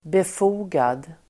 Ladda ner uttalet
Uttal: [bef'o:gad]